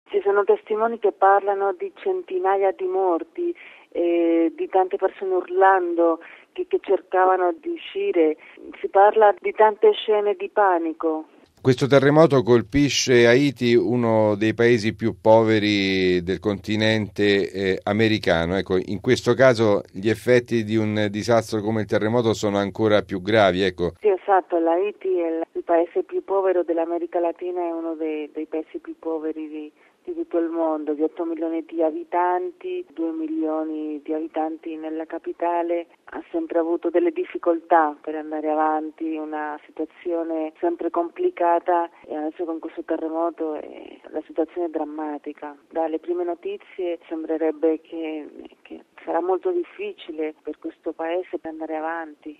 ha raggiunto telefonicamente a Cuba